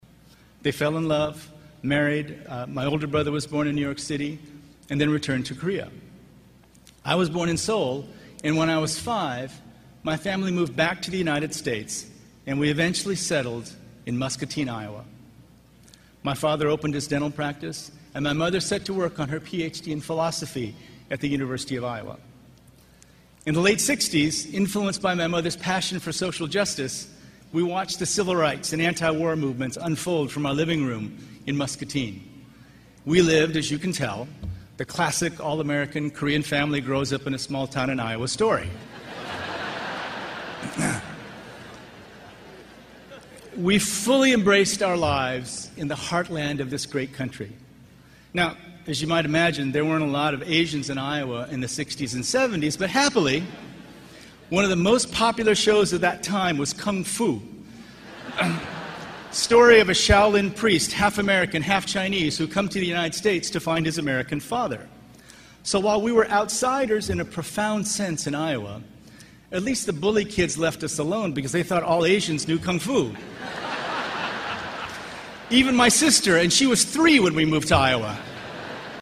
公众人物毕业演讲 第65期:金墉美国东北大学(4) 听力文件下载—在线英语听力室